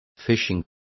Also find out how pesca is pronounced correctly.